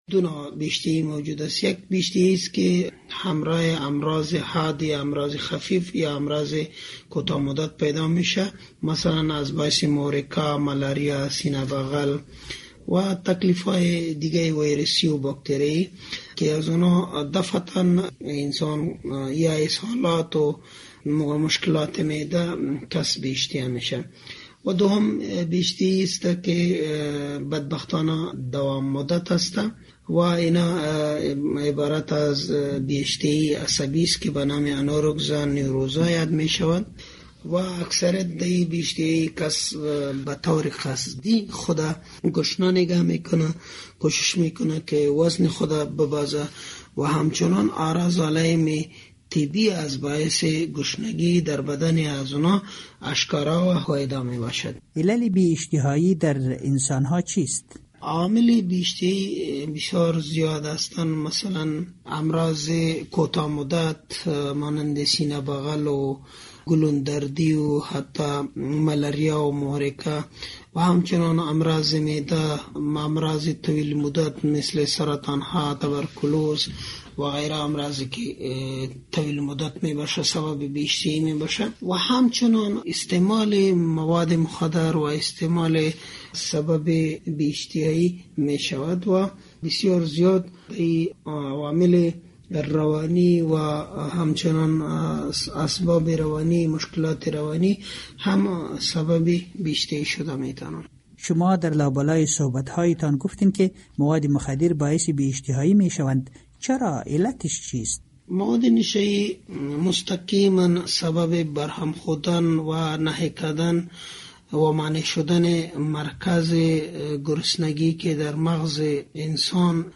معتادانی که در برنامه کاروان زهر با آنان صحبت شده، اکثر شان گفته اند که تمام توجه آنان بر مواد مخدر متمرکز می باشد و اشتها برای خوردن ندارند.